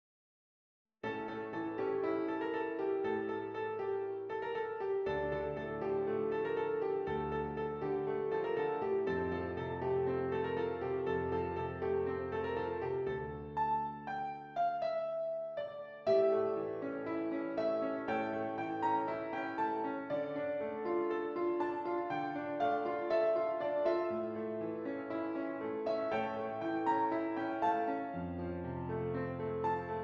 D Minor
Moderately